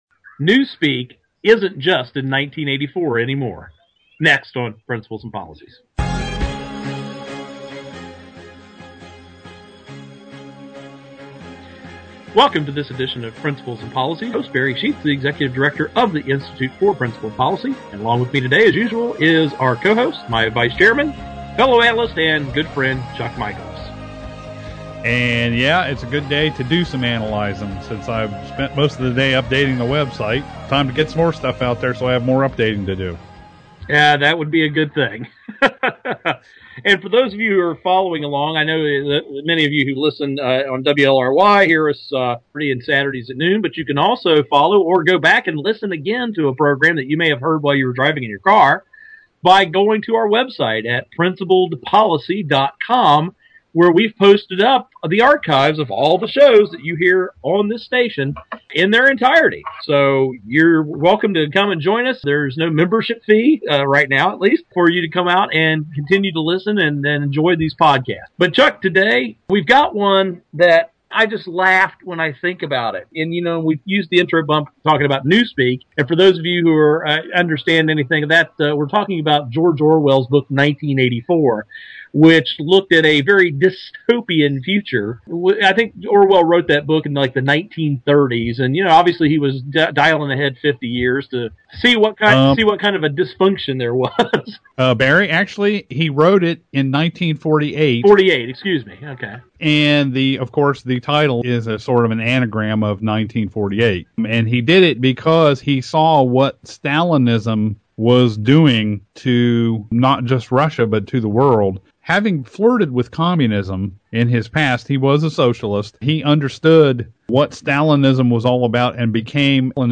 Our Principles and Policies radio show for Monday February 20, 2012.